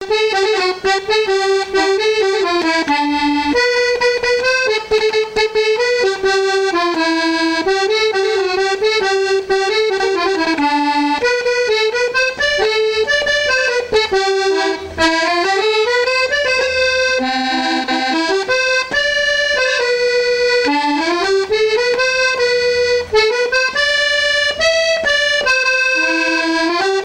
Aizenay
danse : paso-doble
Pièce musicale inédite